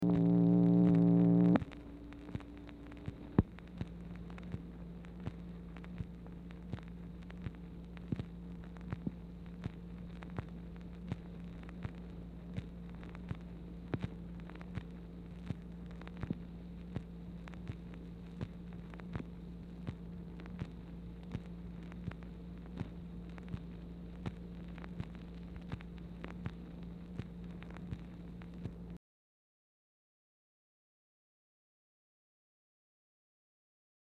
Telephone conversation # 4445, sound recording, MACHINE NOISE, 7/30/1964, time unknown | Discover LBJ
Dictation belt